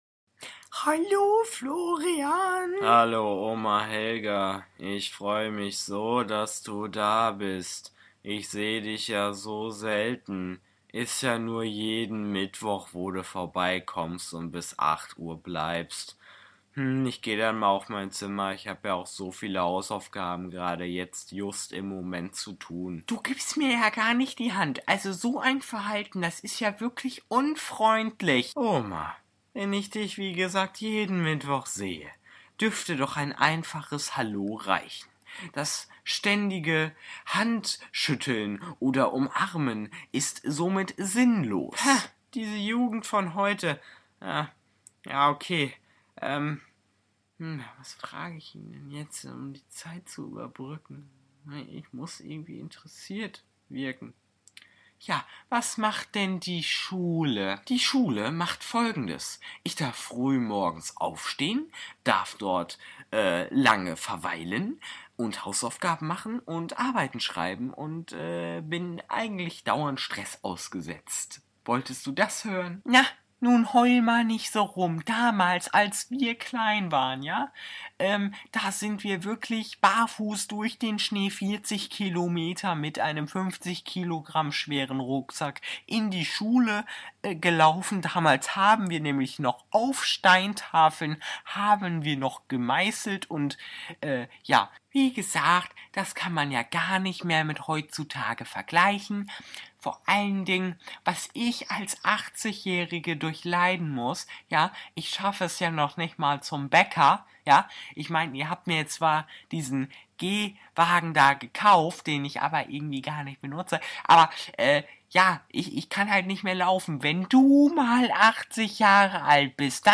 Jeden Mittwoch... (Hörspiel)